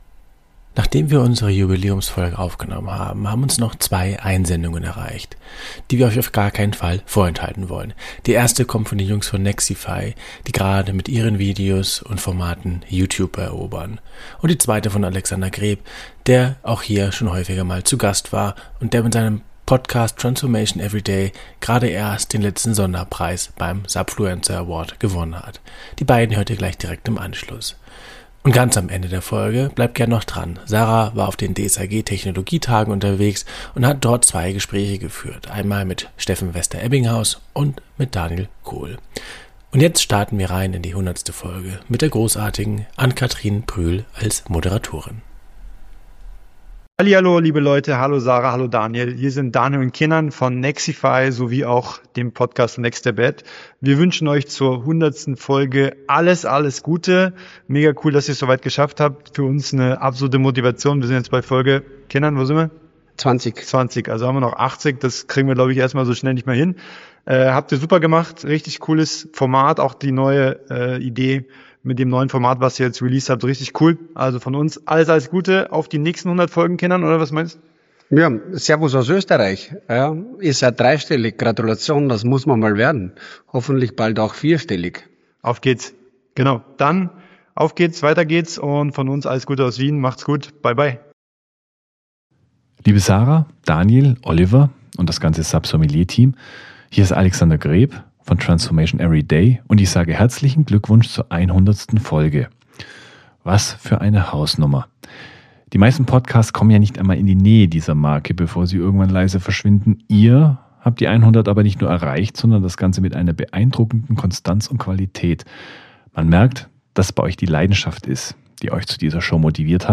Wir haben diverse Einsendungen bekommen, die wir in der Folge einspielen.